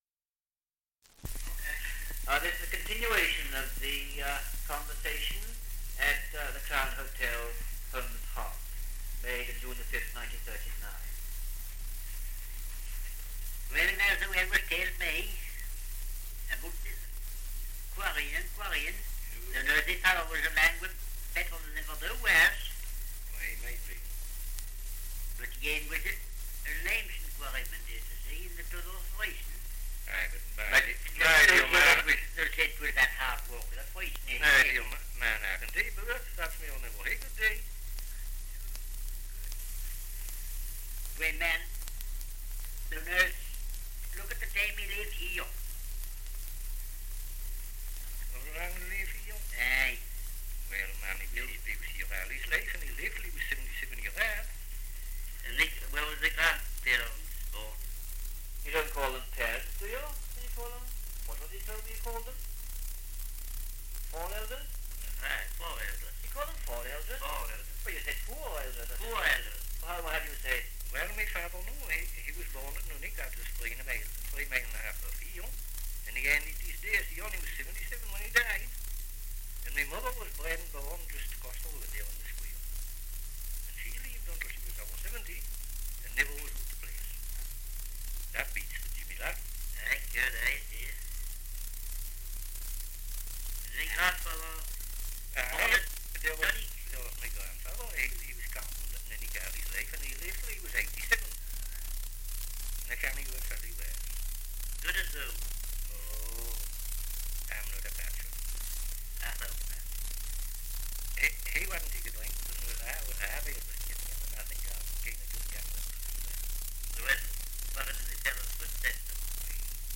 Dialect recording in Humshaugh, Northumberland
78 r.p.m., cellulose nitrate on aluminium